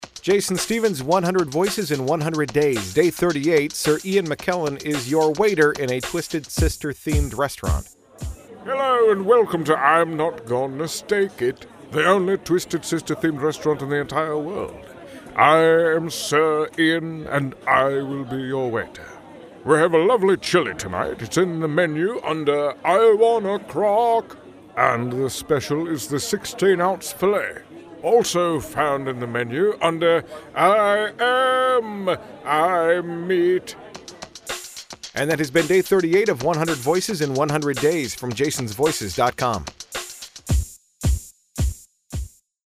Tags: 100 celebrity voices, celebrity impressions, Sir Ian McKellen impersonation